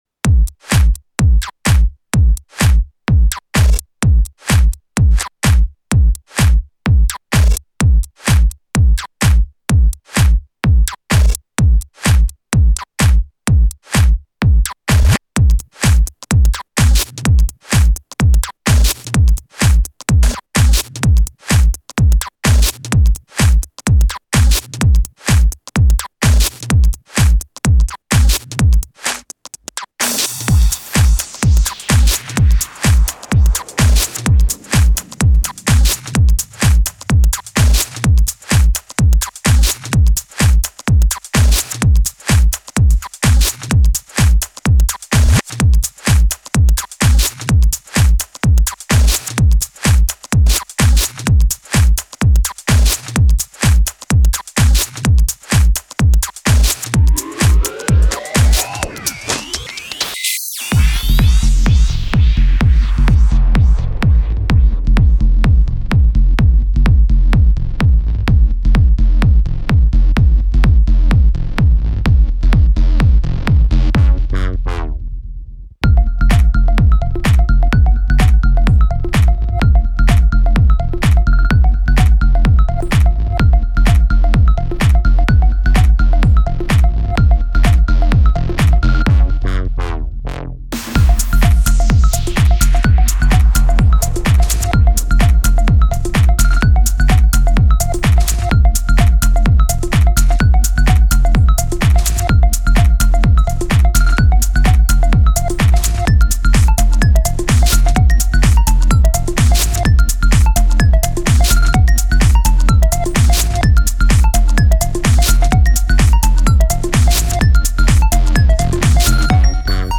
Keeping the electro house feel but adding a darker tone
glitch, electro, club, house